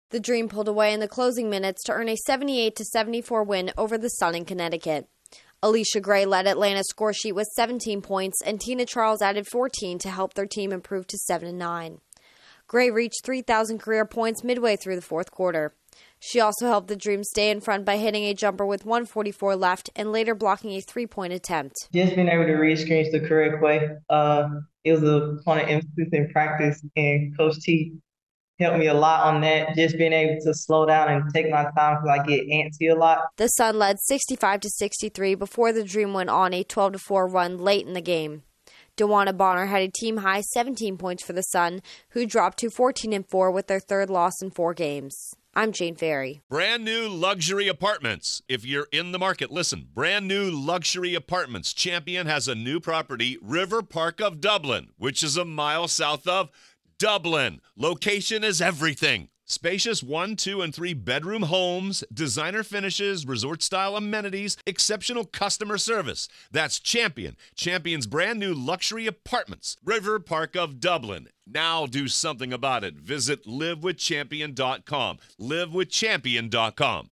The Dream pick up a big road win. Correspondent